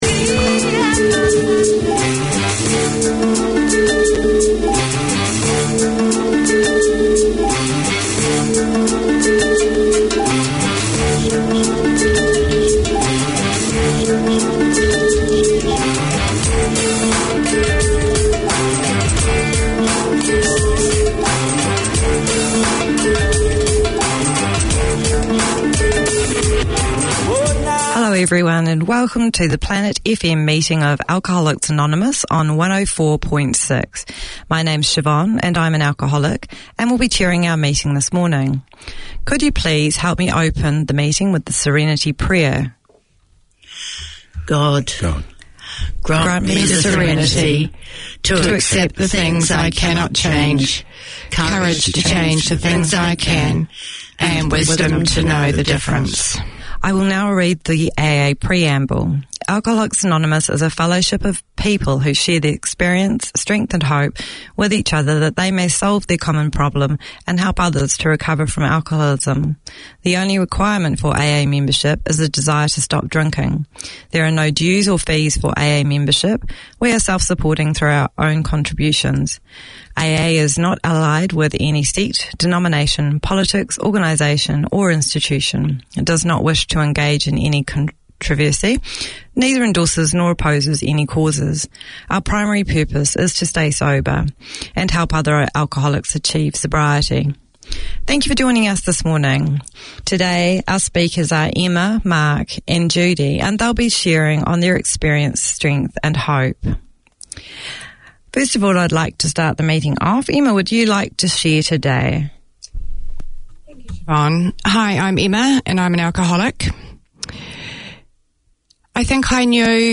Hear guests on current topics, who speak candidly about what's good and what's not good for their mental health.